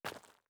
02_室外_3.wav